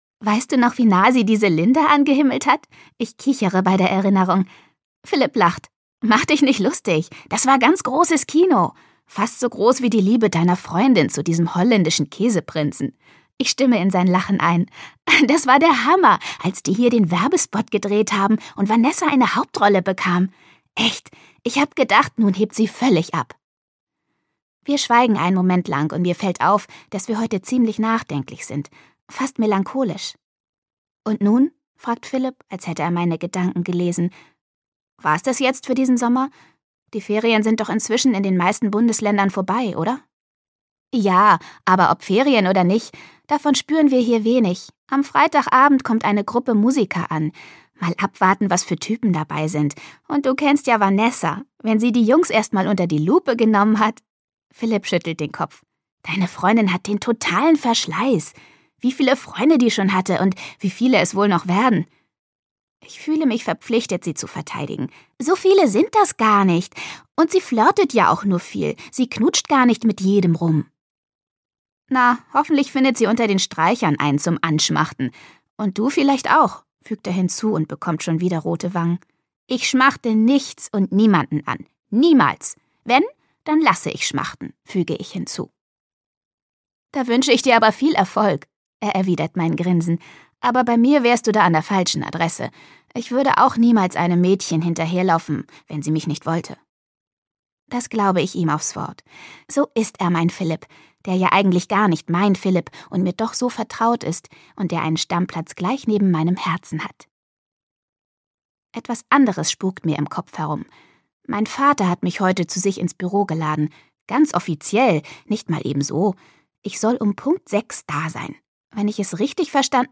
Freche Mädchen: Italiener sind zum Küssen da - Martina Sahler - Hörbuch